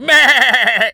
sheep_baa_bleat_angry_02.wav